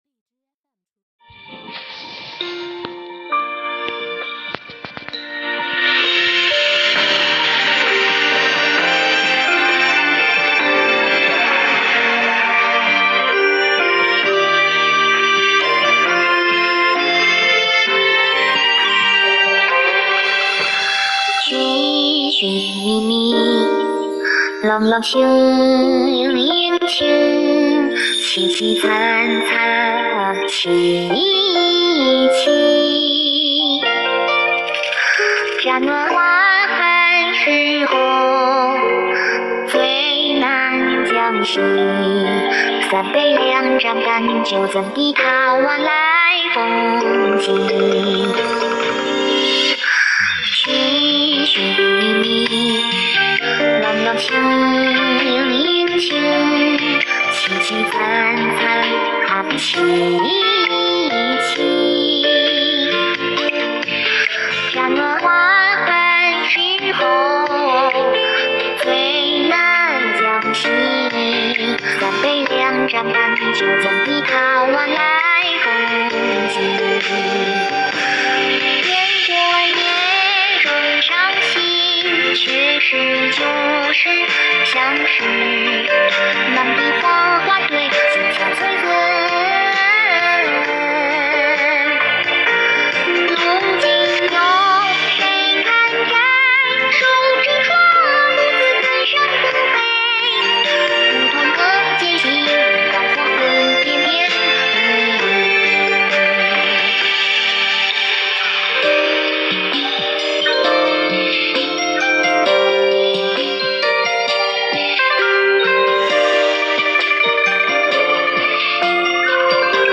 配乐歌曲：诗话
演唱：洛天依